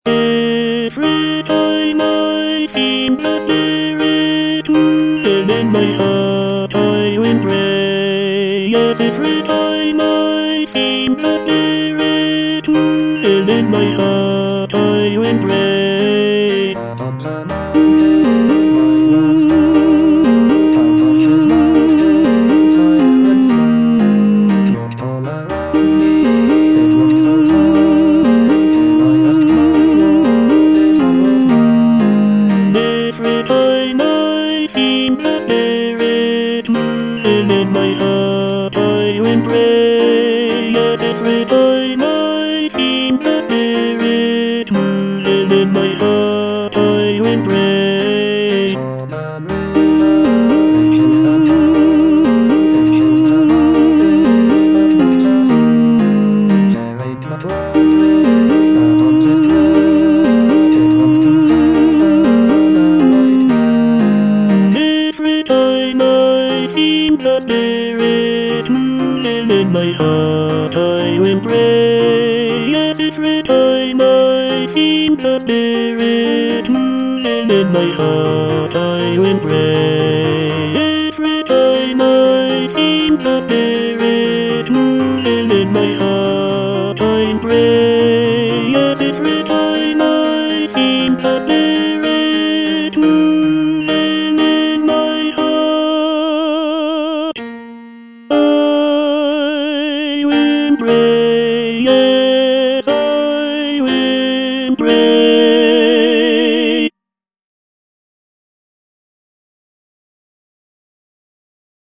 Tenor (Voice with metronome)